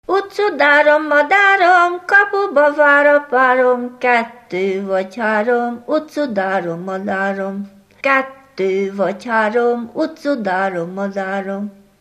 Dunántúl - Tolna vm. - Decs
ének
Műfaj: Ugrós
Stílus: 1.1. Ereszkedő kvintváltó pentaton dallamok
Kadencia: 7 (1) 4 1